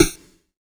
SNARE 35  -L.wav